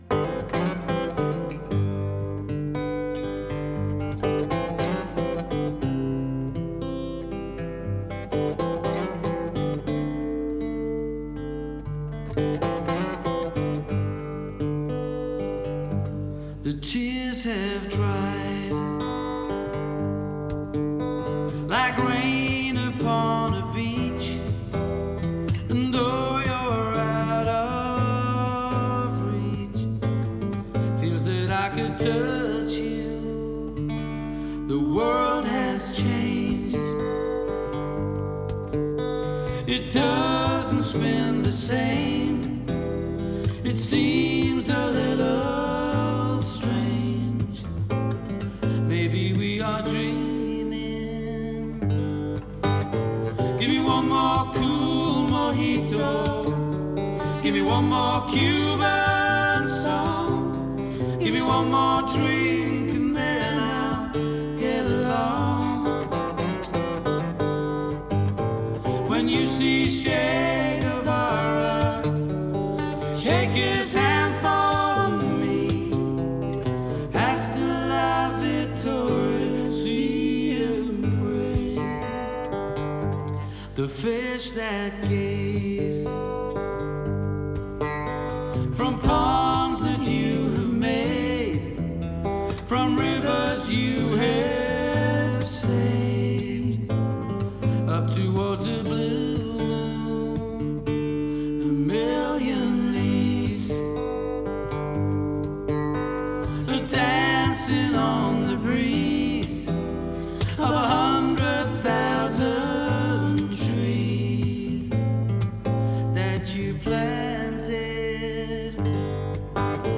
A sad lament